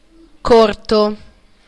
Ääntäminen
IPA: /kuʁ/